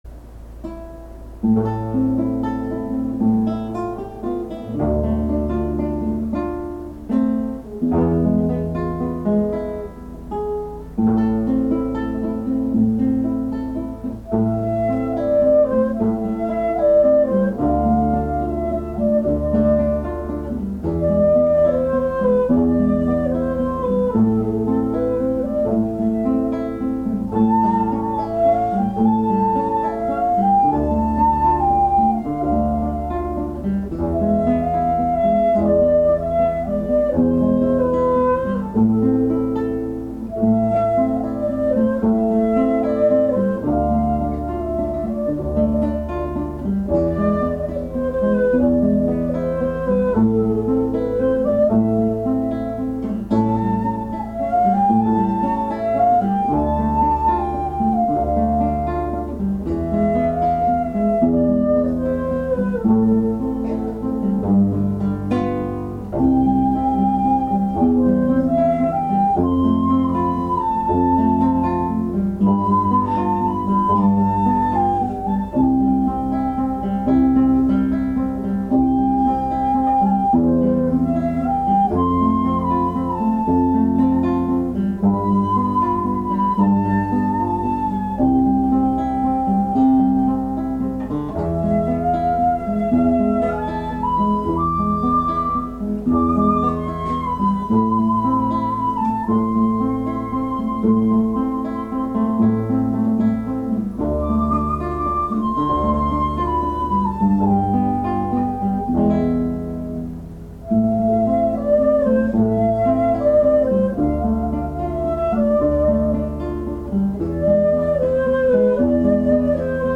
これも会場の皆さんと一緒に歌っていただきました。
音程さえ気をつければ西洋のハーモニーに尺八演奏を重ねても全く違和感がないことを、これも再認識した次第です。